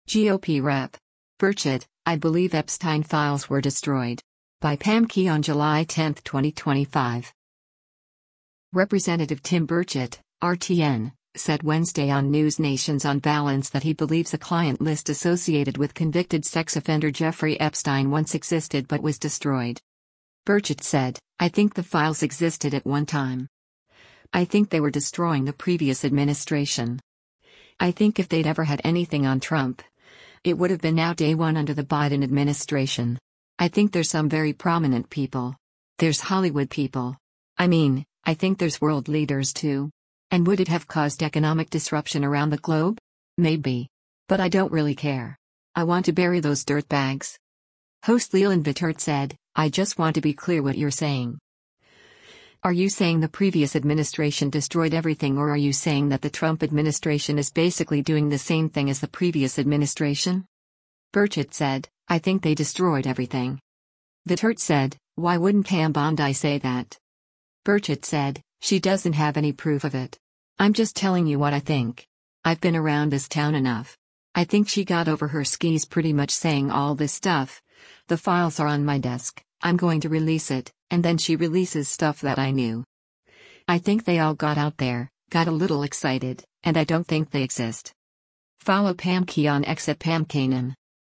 Representative Tim Burchett (R-TN) said Wednesday on NewsNation’s “On Balance” that he believes a client list associated with convicted sex offender Jeffrey Epstein once existed but was “destroyed.”